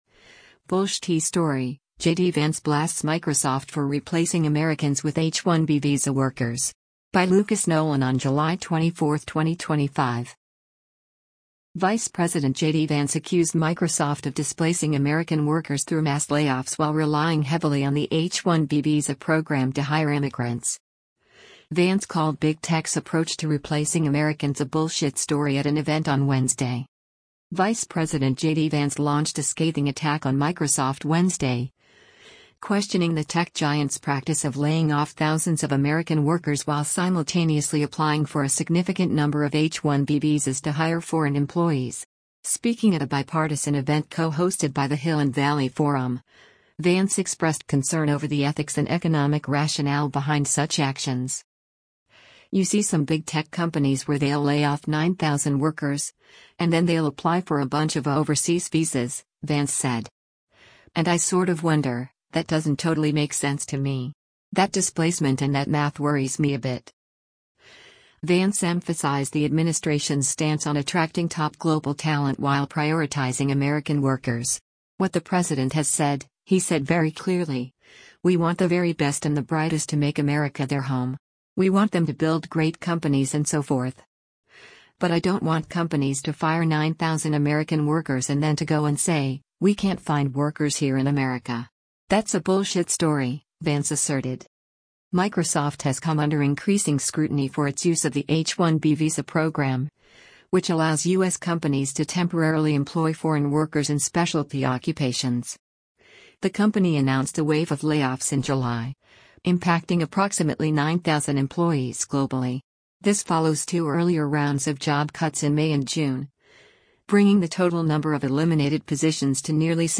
Vice President JD Vance accused Microsoft of displacing American workers through mass layoffs while relying heavily on the H-1B visa program to hire immigrants. Vance called Big Tech’s approach to replacing Americans “a bullshit story” at an event on Wednesday.
Speaking at a bipartisan event co-hosted by the Hill and Valley Forum, Vance expressed concern over the ethics and economic rationale behind such actions.